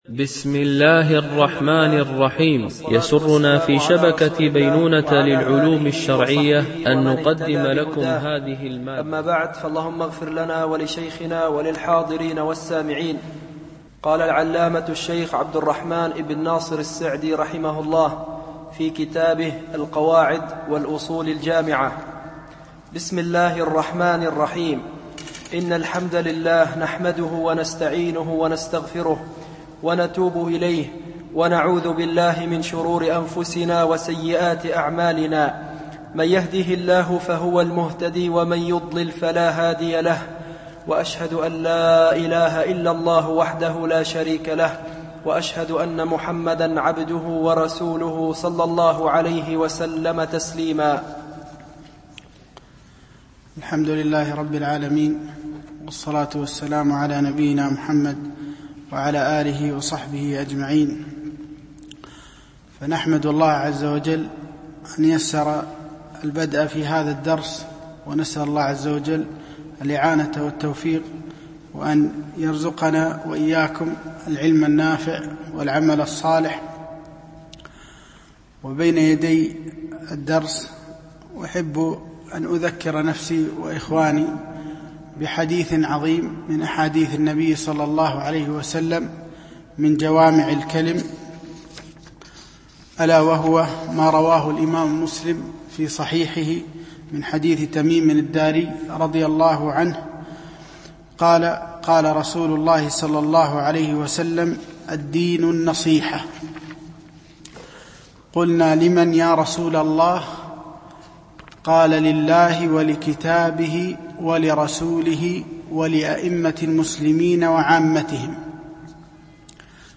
الدرس 1 ( المقدمة )